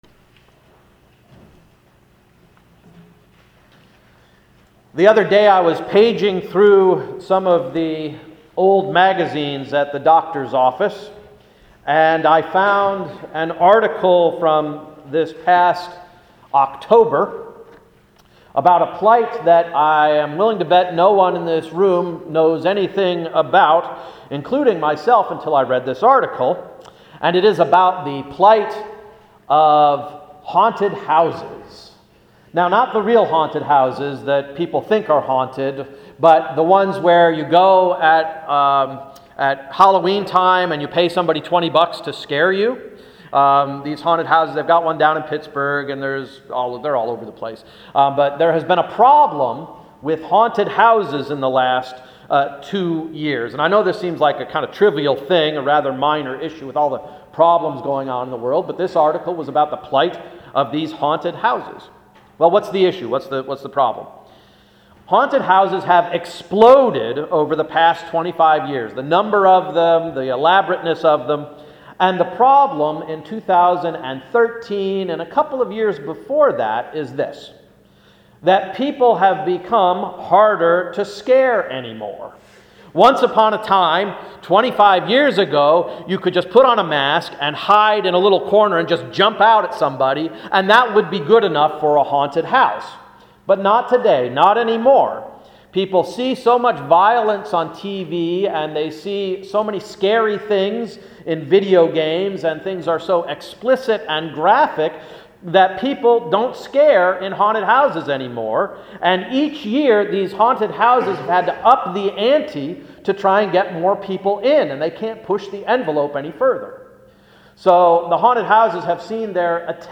Sermon of March 16–“Happy Birthday”